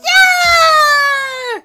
Worms speechbanks
Perfect.wav